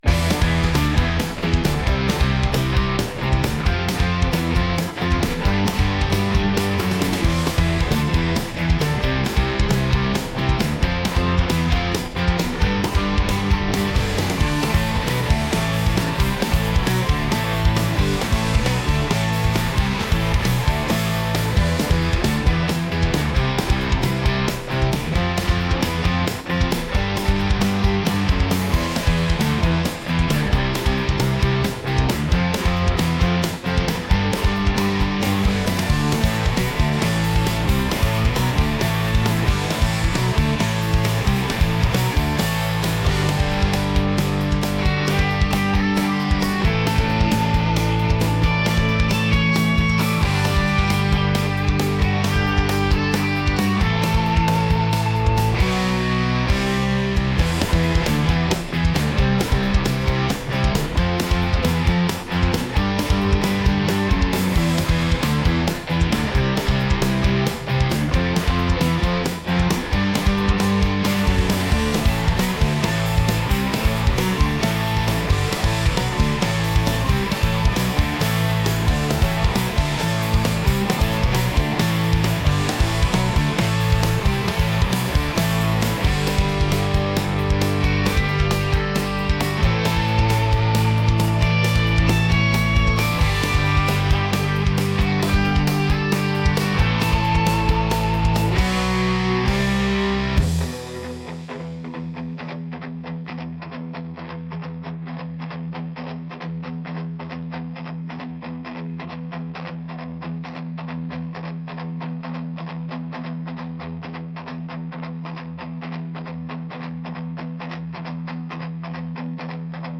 rock | energetic | upbeat